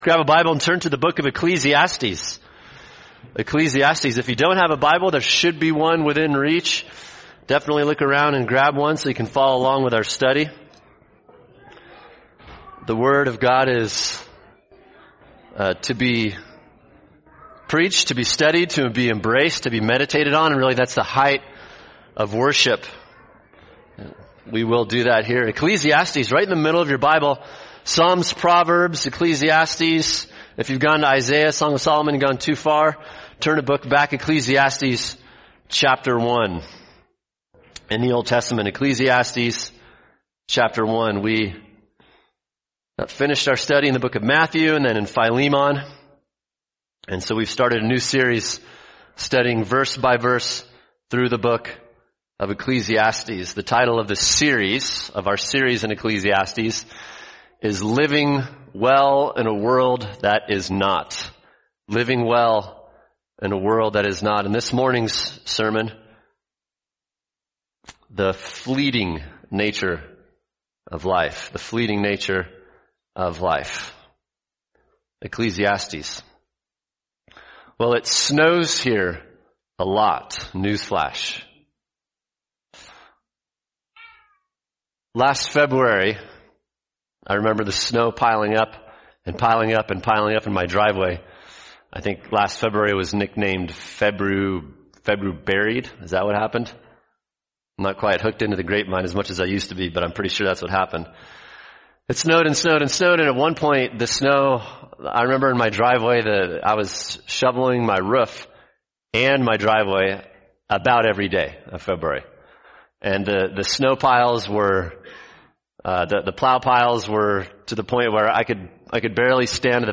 [sermon] Ecclesiastes 1:1-11 The Fleeting Nature of Life | Cornerstone Church - Jackson Hole